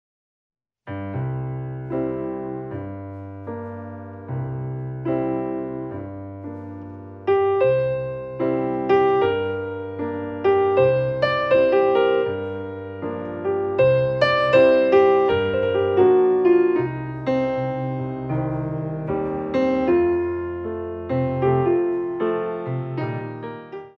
Ballet class music for Intermedite Level
Beautifully recorded on a Steinway and Sons Grand Piano